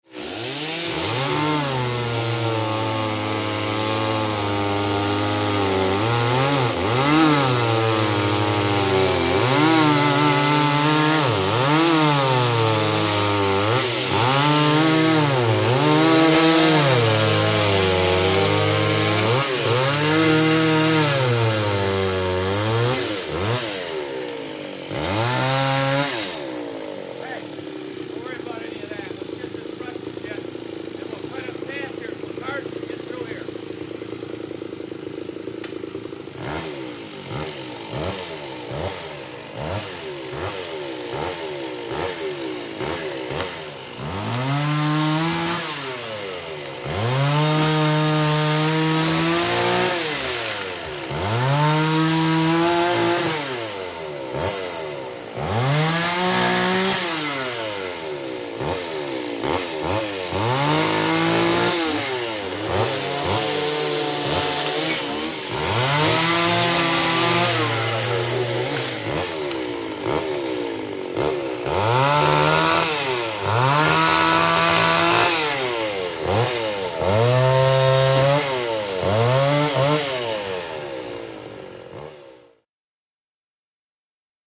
2ChainSawsTrimTree PE699601
MACHINES - CONSTRUCTION & FACTORY CHAINSAW: EXT: Two chain saws trimming fallen tree.